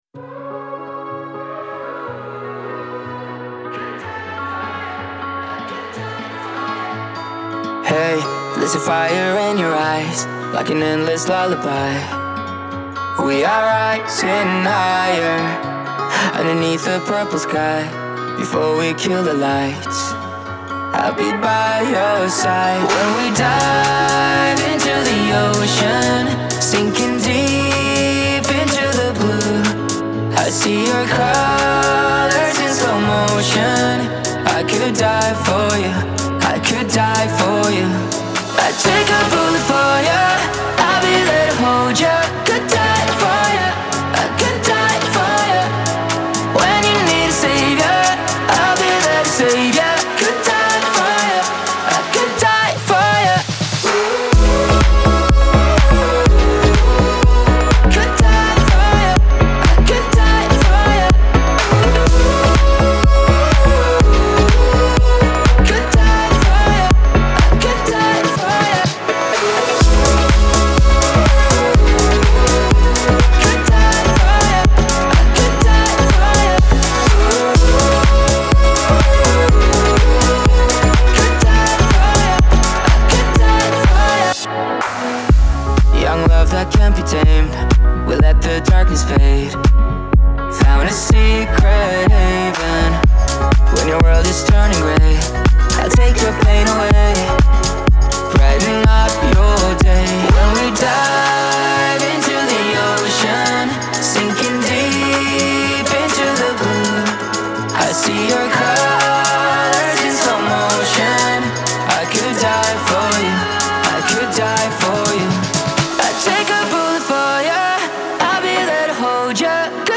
Genre : Électronique, Dance